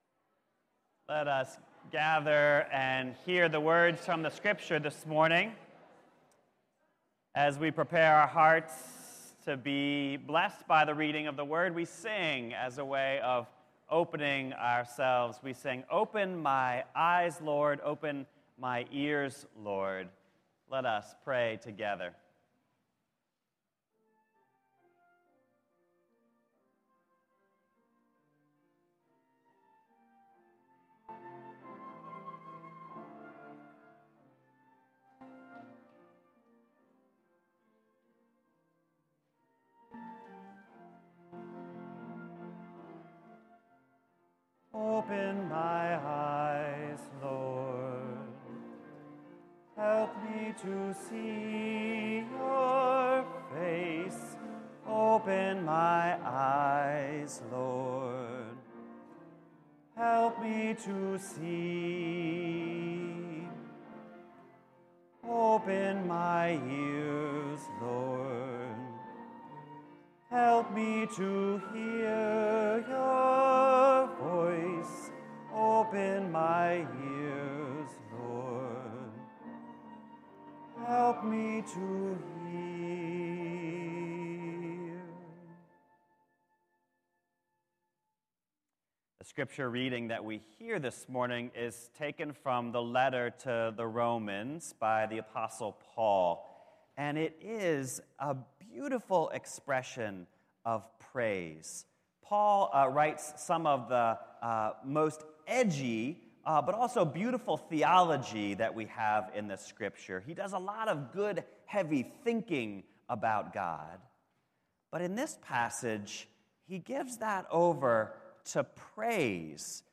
On July 9th, we continue our summer sermon series of request made by members of NDPC. This week, we visit the question of religious plurlaism: do all roads lead to God? How do we live within a religious tradition that makes “ultimate” faith claims when we know that other religions make the same claims?